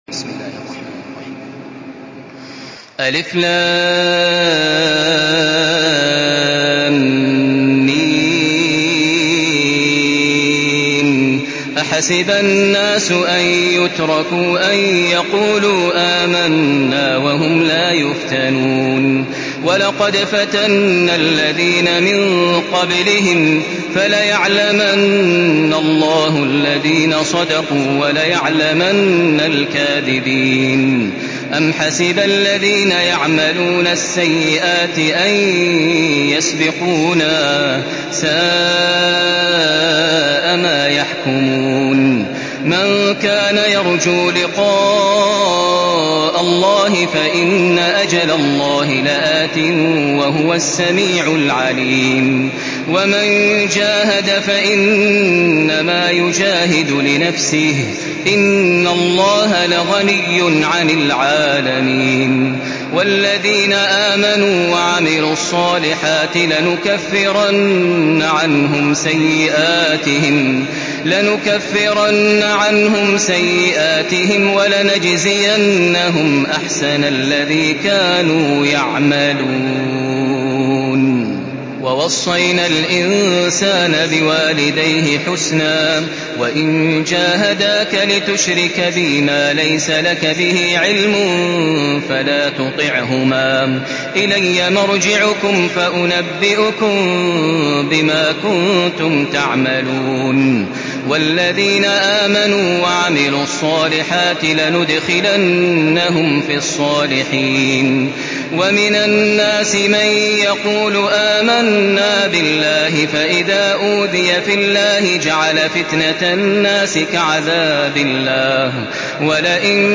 Une récitation touchante et belle des versets coraniques par la narration Hafs An Asim.
Murattal